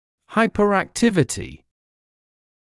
[ˌhaɪpəræk’tɪvətɪ][ˌхайперэк’тивэти]гиперактивность